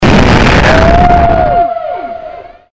Whale